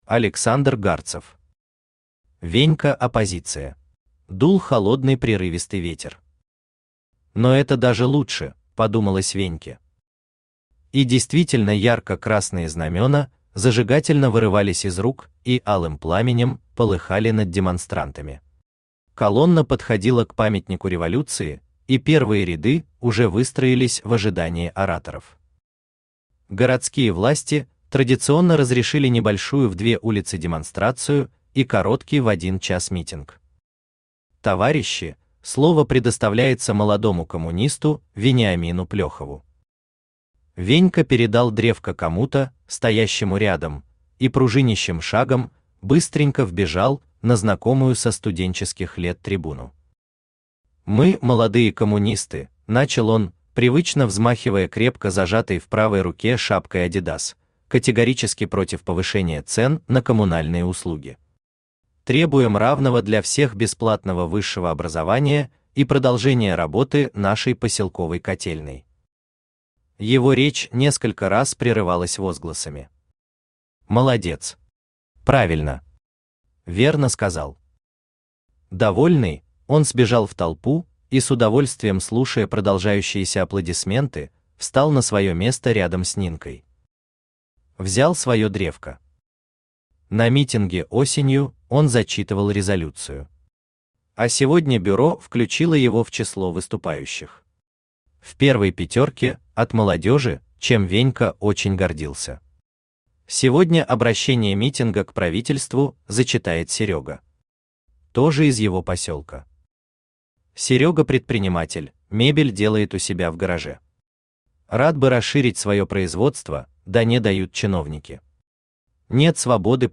Аудиокнига Венька-оппозиция | Библиотека аудиокниг
Aудиокнига Венька-оппозиция Автор Александр Гарцев Читает аудиокнигу Авточтец ЛитРес.